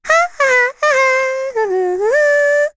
Rehartna-Vox_Hum2_kr.wav